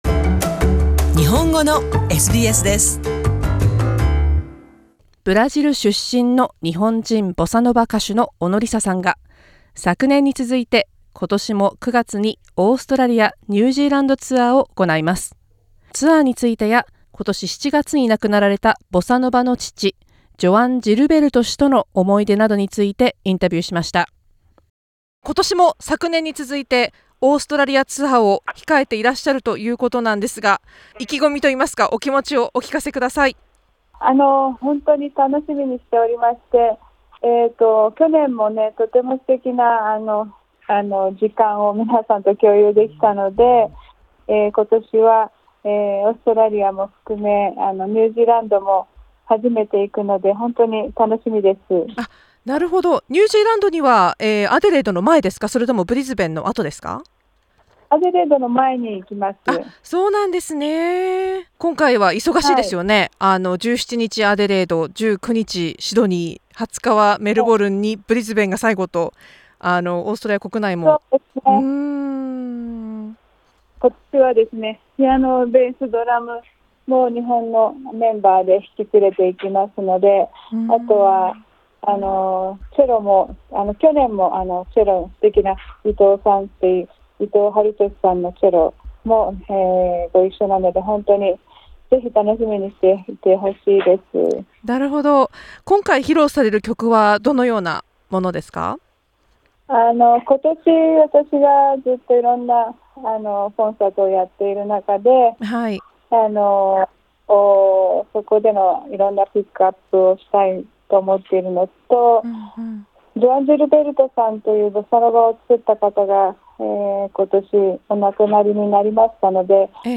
今年の公演についてやオーストラリアの印象について、更に今年7月に亡くなった伝説的なボサノバ歌手・ギター奏者ジョアン・ジルベルトさんとの思い出などについて、お話を伺いました。